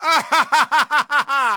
taunt1.ogg